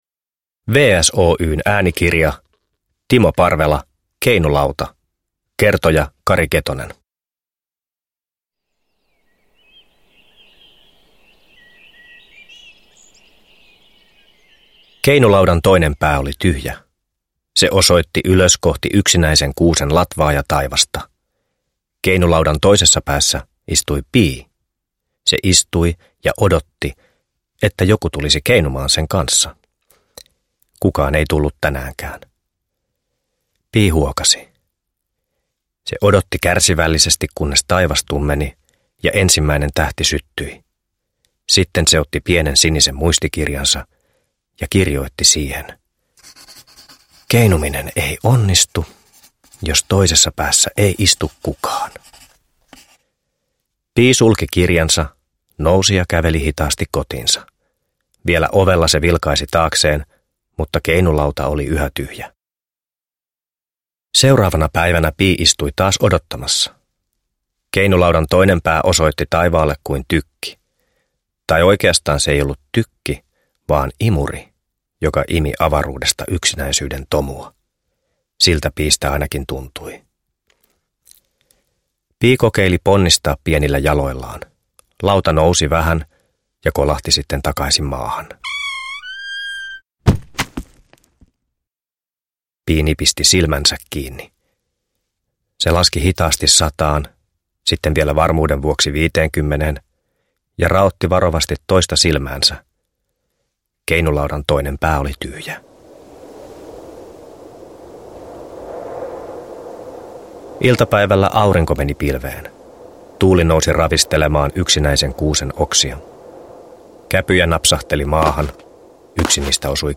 Keinulauta – Ljudbok – Laddas ner